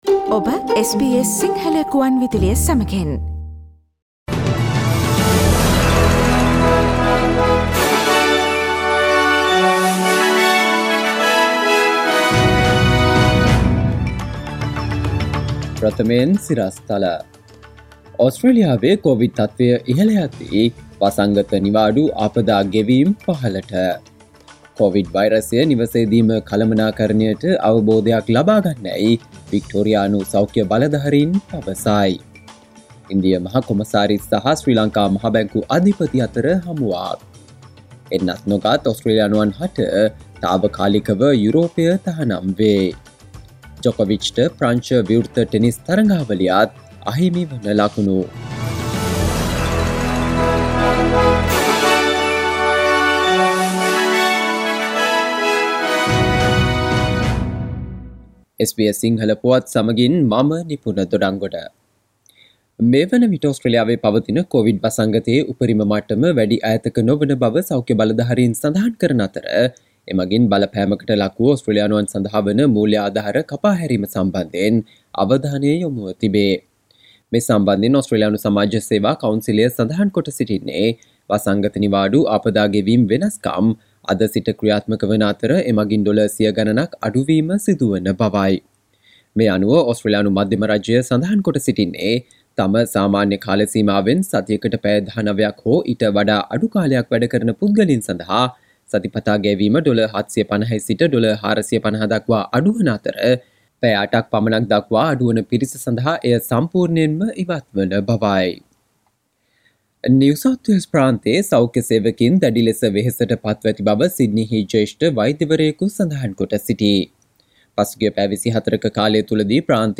සවන්දෙන්න 2022 ජනවාරි 18 වන අඟහරුවාදා SBS සිංහල ගුවන්විදුලියේ ප්‍රවෘත්ති ප්‍රකාශයට...